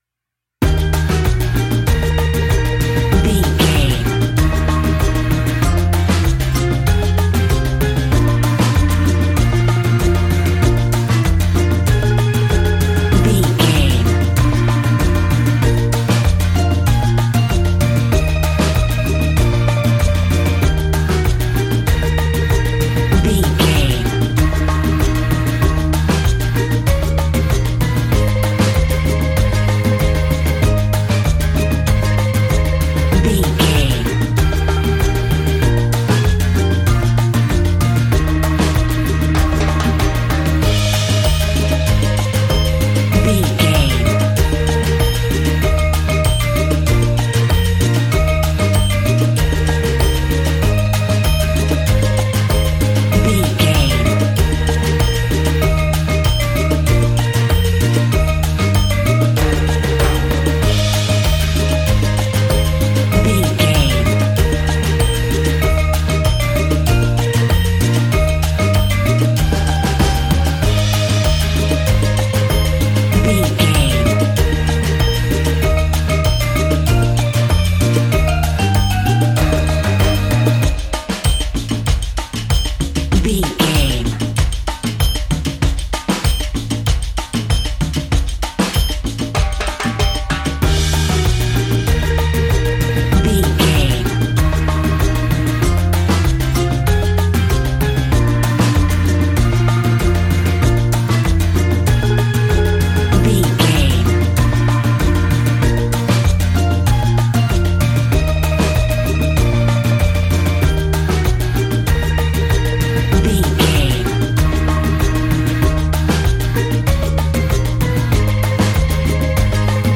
Ionian/Major
E♭
cheerful/happy
mellow
drums
electric guitar
percussion
horns
electric organ